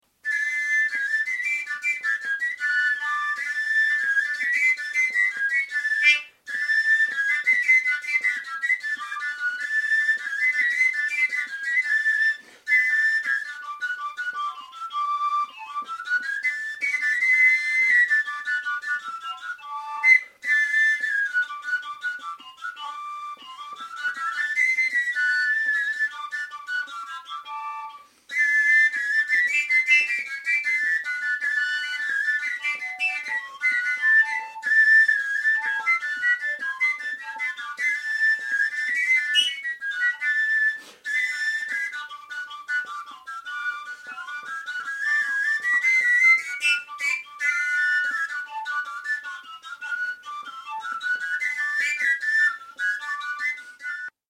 - Mignardises nordiques aux flûtes harmoniques suédoises
halling.mp3